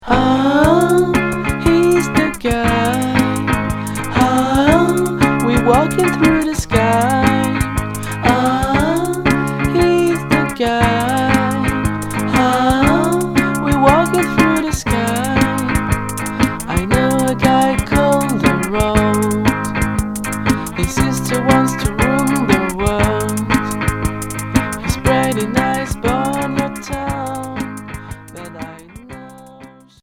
Pop indé